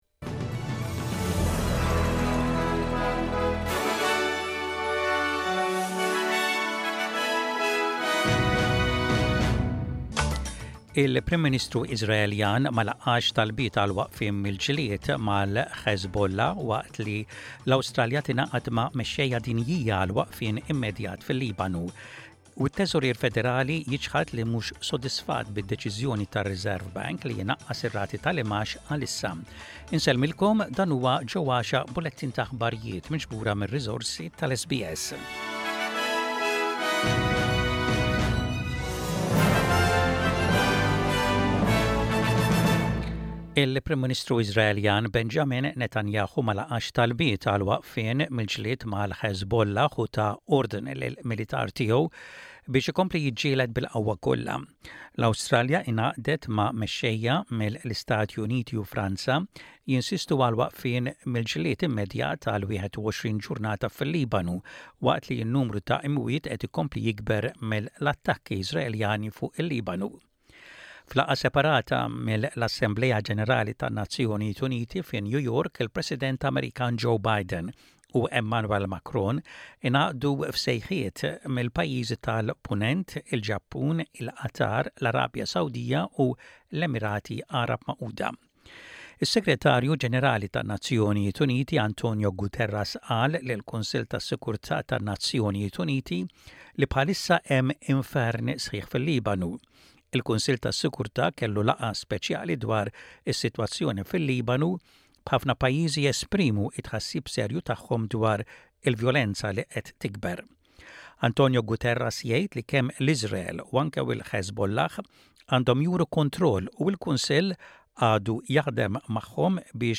SBS Radio | Aħbarijiet bil-Malti: 27.09.24